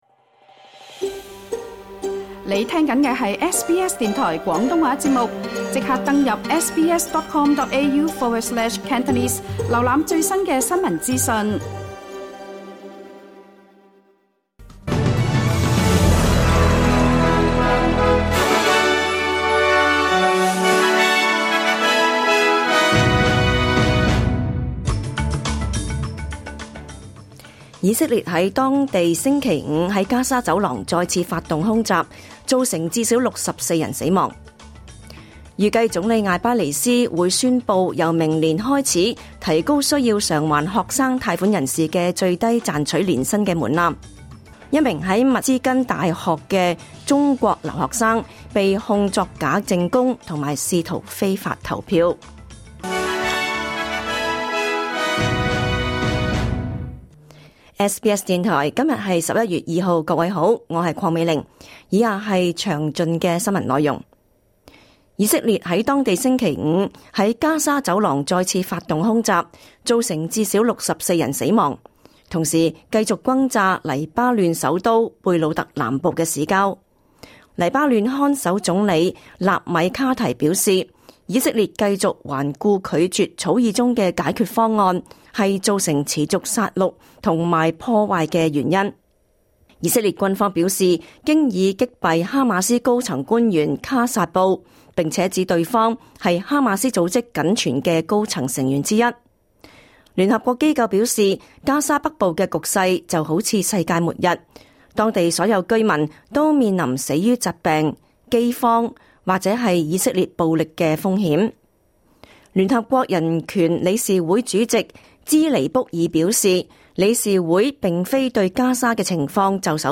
2024 年11 月 2 日 SBS 廣東話節目詳盡早晨新聞報道。